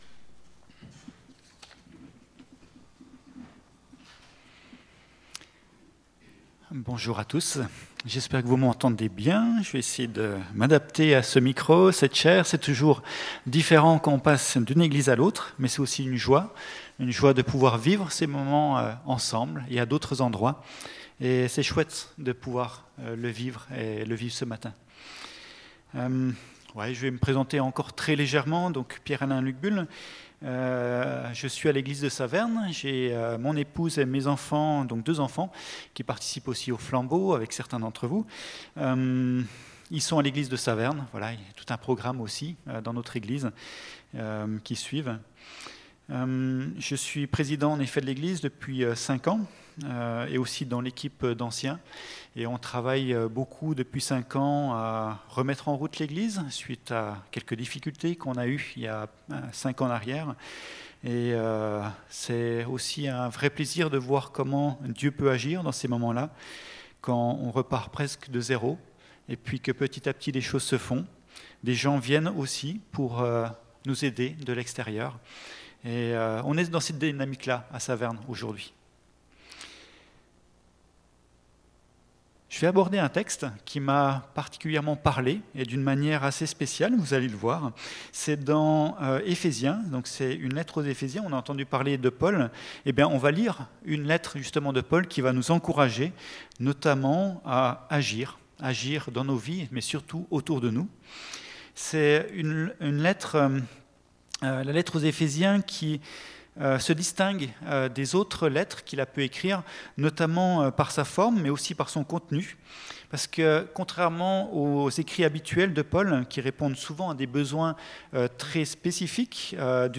Messages
Ecoutez les différents messages de l'église évangélique de Bouxwiller